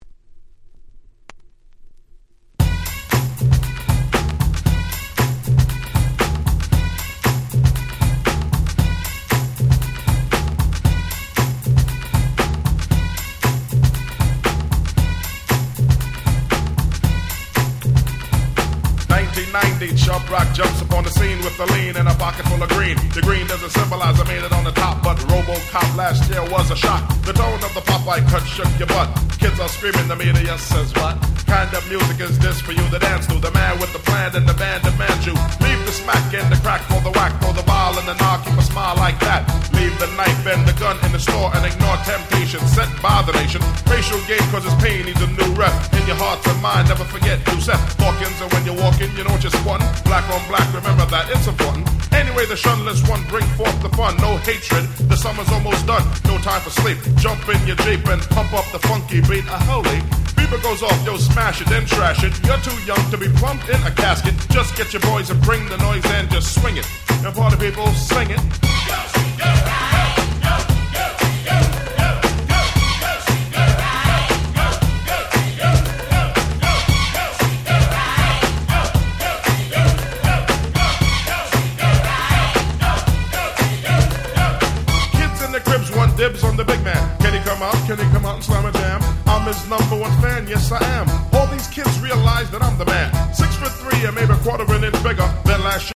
本盤は90'sの人気Hip Hop Classicsばかりを全6曲収録！！